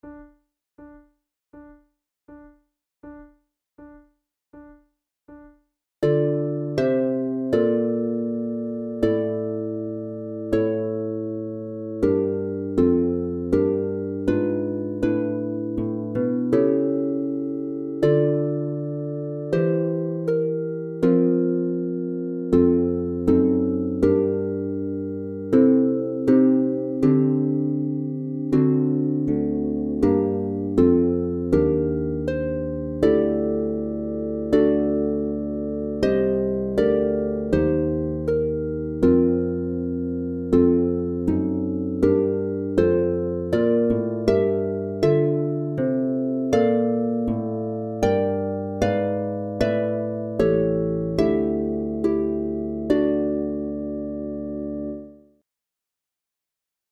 KeyD minor
Tempo80 BPM
Contemporary